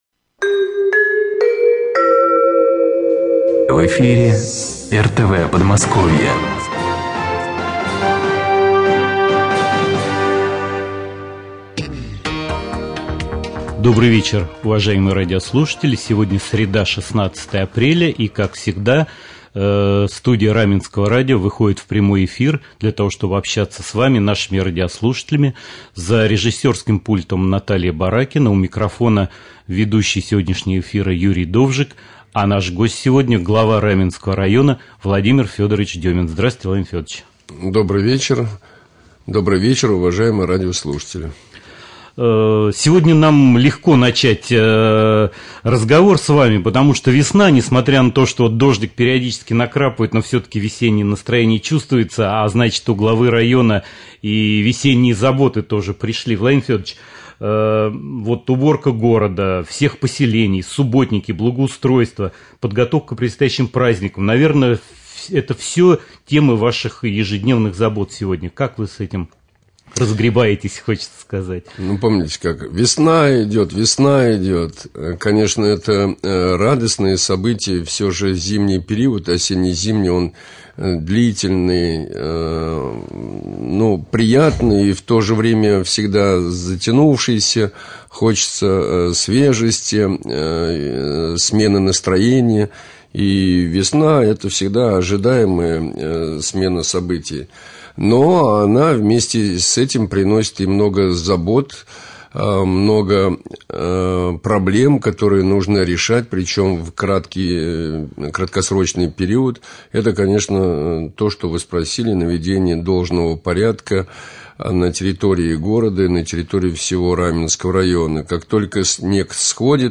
Глава Раменского муниципального района